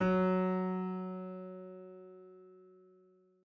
piano-sounds-dev
SoftPiano